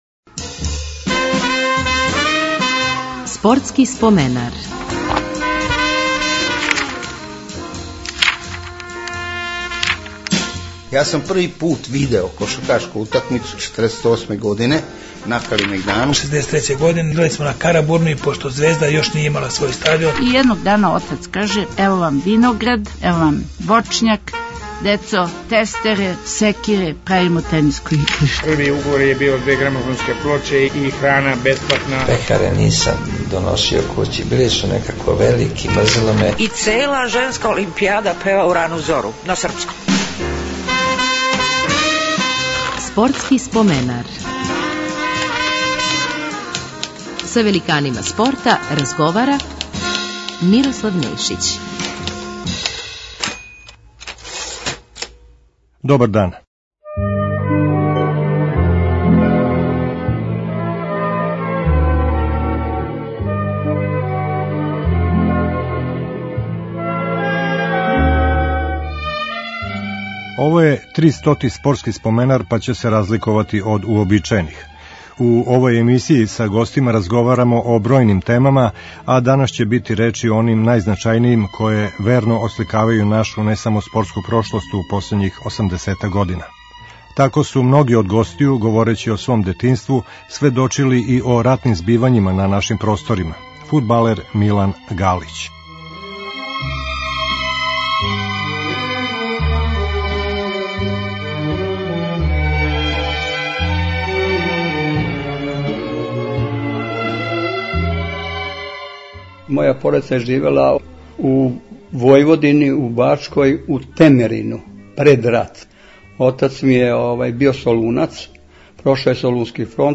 Разликоваће се од уобичајених, јер ће бити колажног типа.